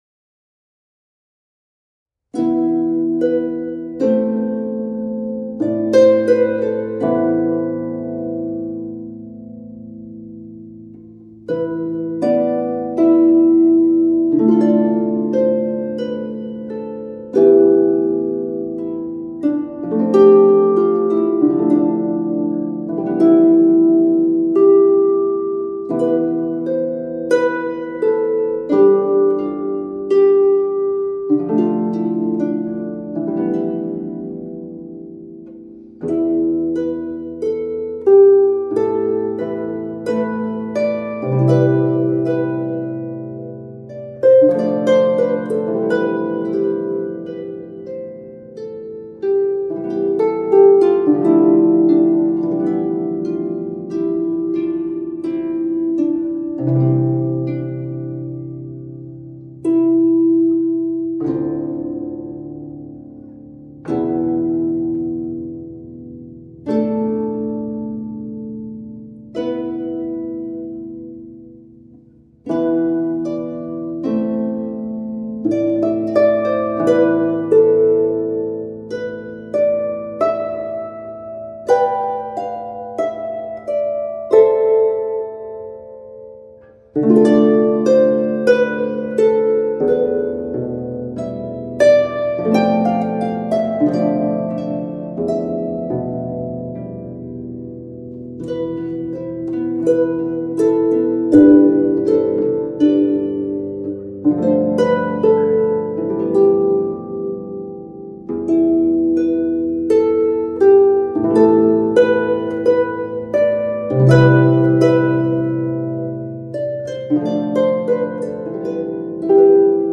Many songs are modal.